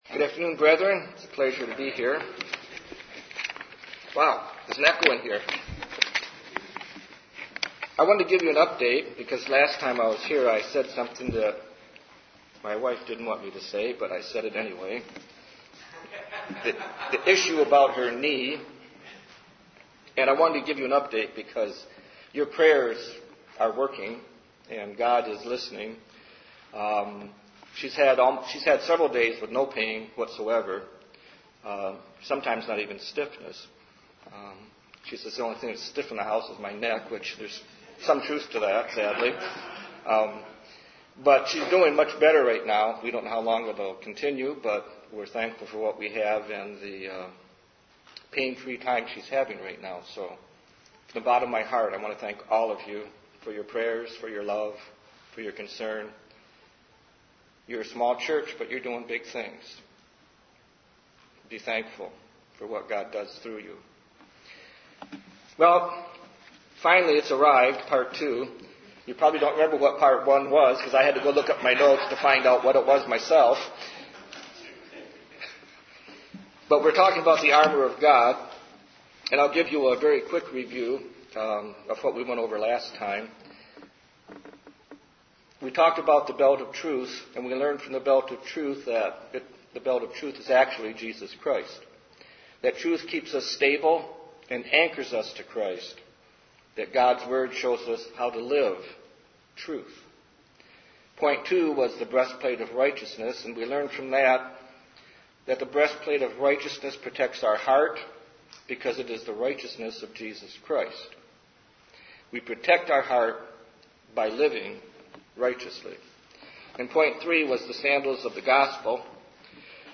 This sermon explains how we can use the Shield of Faith, Helmet of Salvation and Sword of the Spirit, all components of the Armor of God.
Given in Detroit, MI